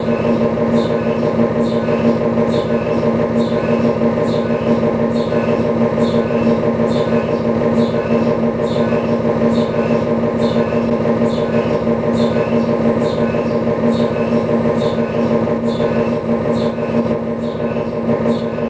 MRI 1.3 Gauss Test